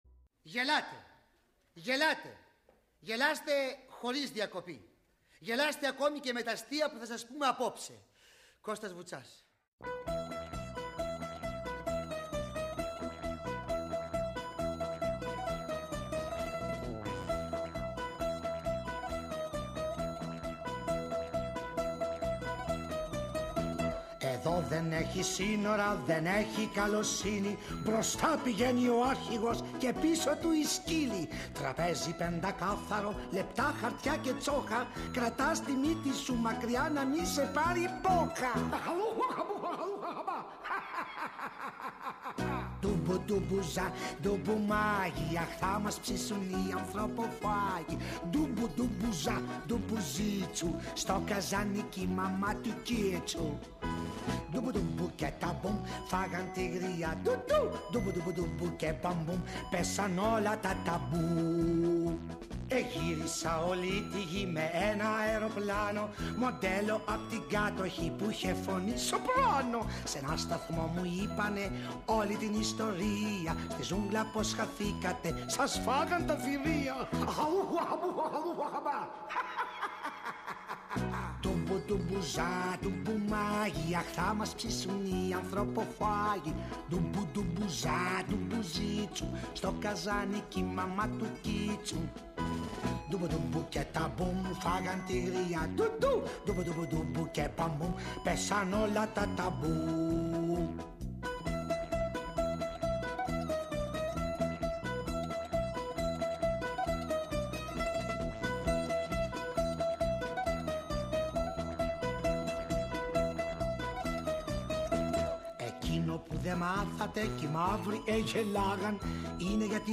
Η εκπομπή επικεντρώνεται σε σπάνια αποσπάσματα από ραδιοφωνικές επιθεωρήσεις -μέσα από το Αρχείο της ΕΡT– που ηχογραφήθηκαν και μεταδόθηκαν από το Εθνικό Ίδρυμα Ραδιοφωνίας την περίοδο 1958-1964.